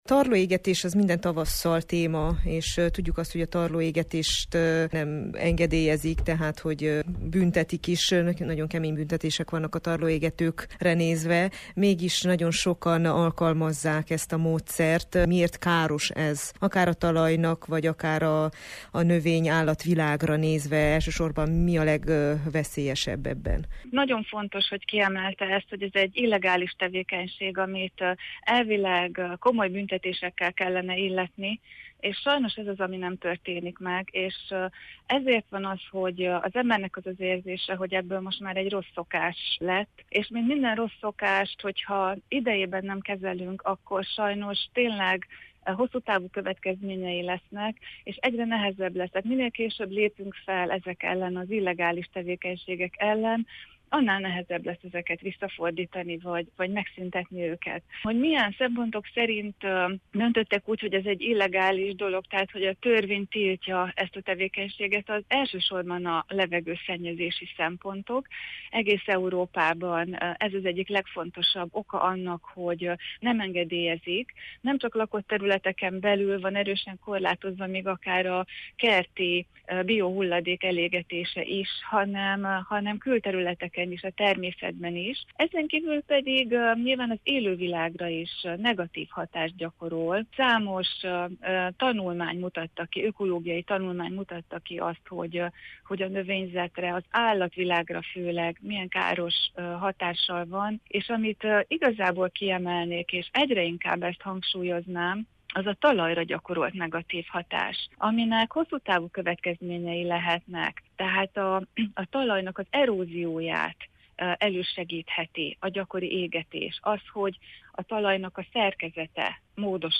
interjúja